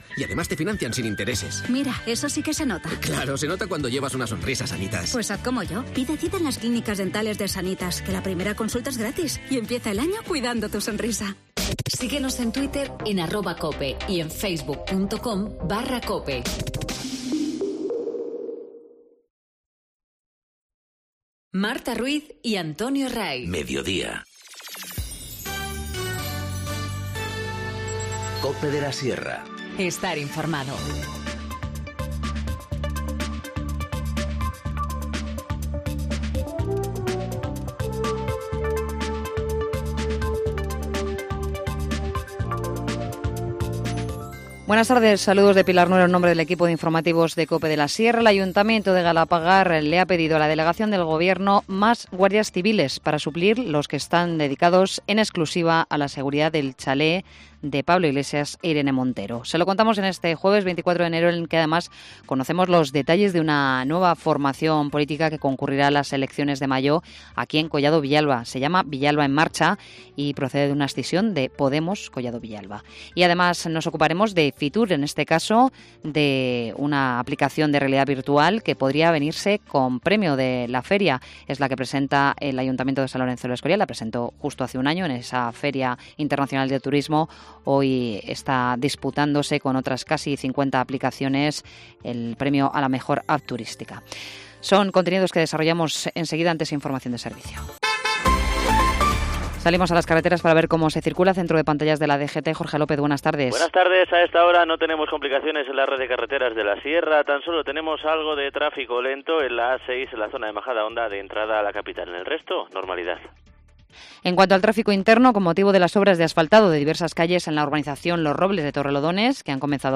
Informativo Mediodía 24 enero- 14:20h